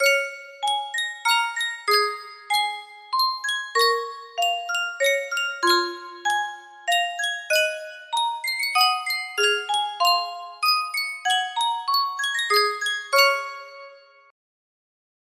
Sankyo Custom Tune Music Box - Madama Butterfly music box melody
Full range 60